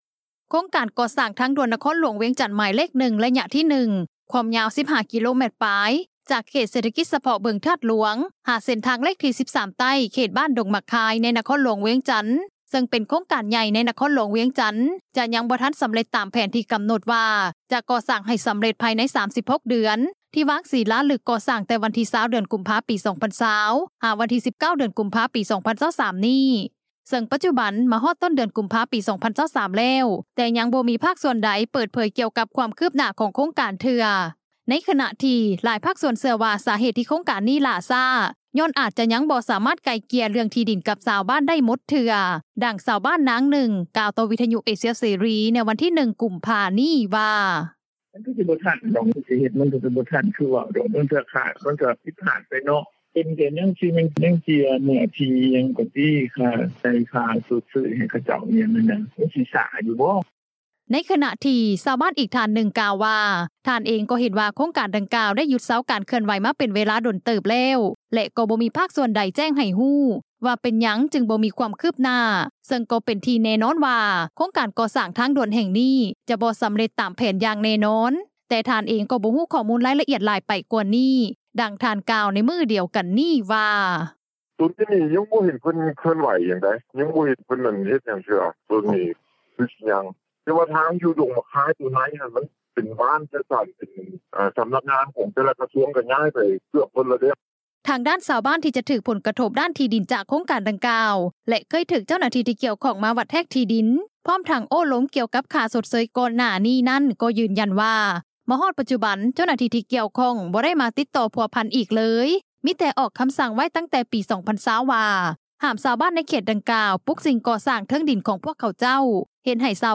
ດັ່ງຊາວບ້ານນາງນຶ່ງ ກ່າວຕໍ່ວິທຍຸ ເອເຊັຽ ເສຣີ ໃນວັນທີ 1 ກຸມພາ  ນີ້ວ່າ: